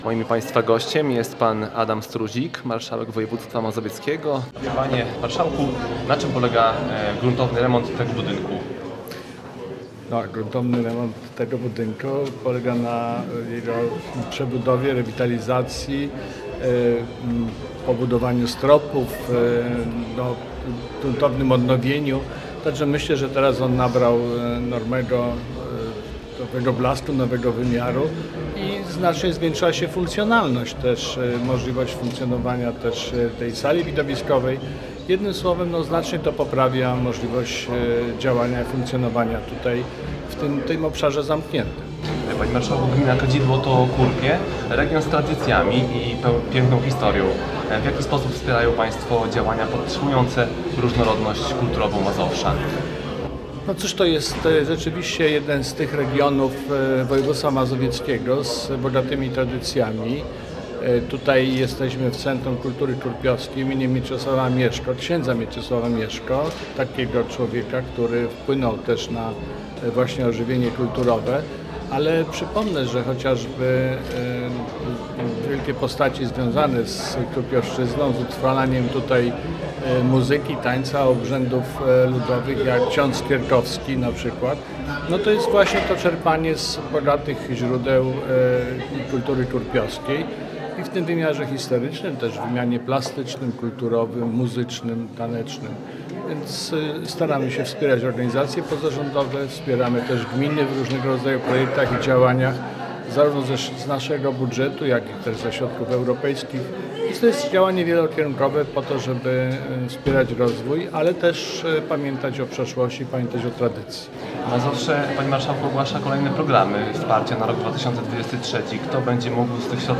Całą rozmowę z Marszałkiem Województwa Mazowieckiego, Adamem Struzikiem udostępniliśmy pod spodem.